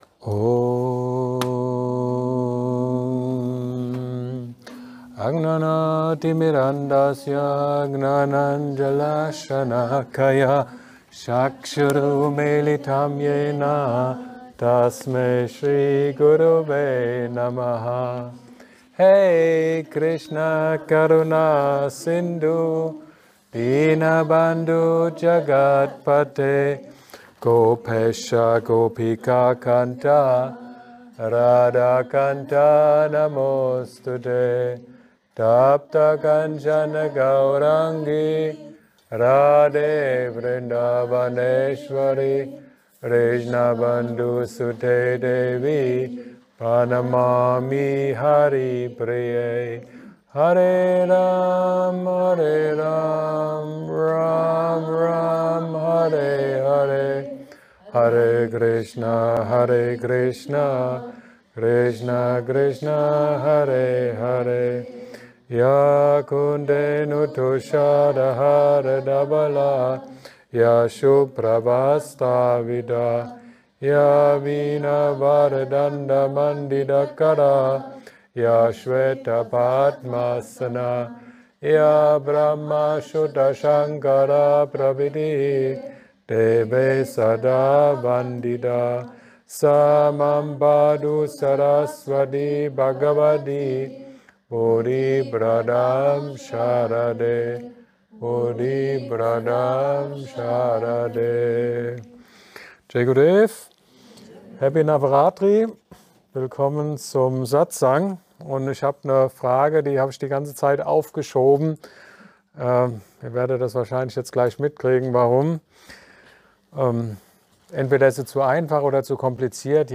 Satsang